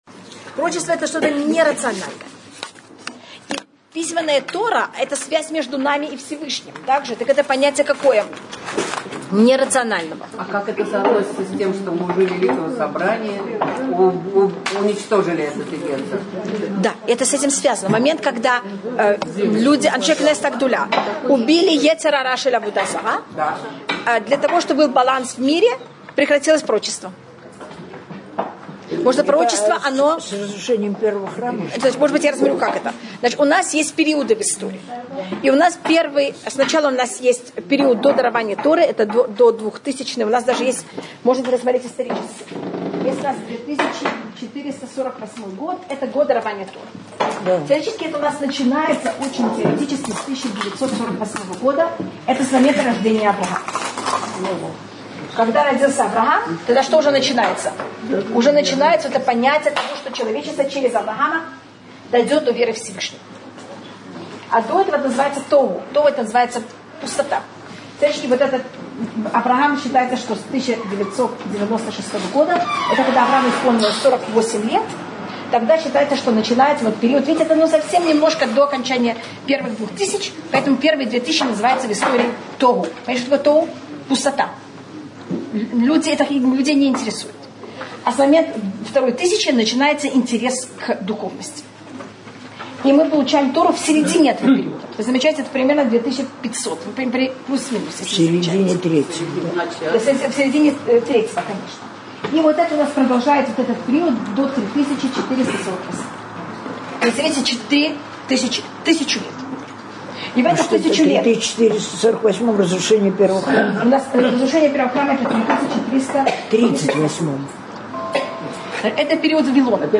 Уроки для женщин